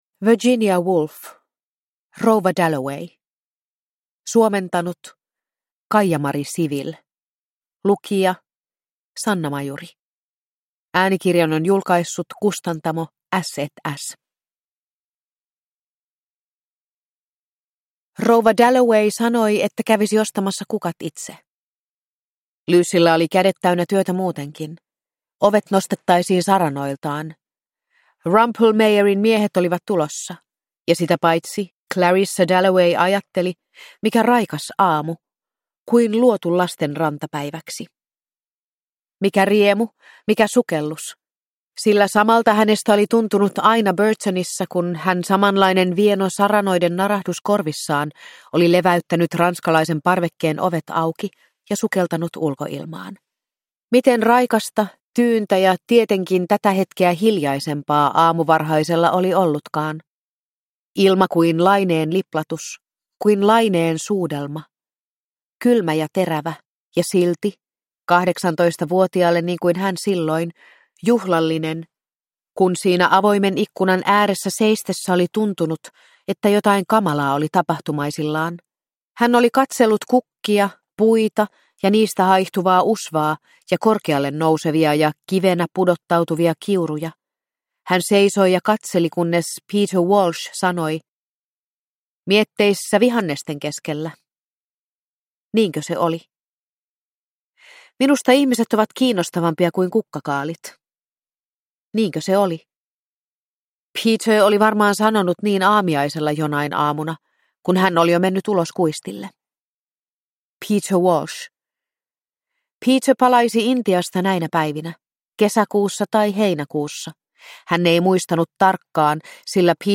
Rouva Dalloway – Ljudbok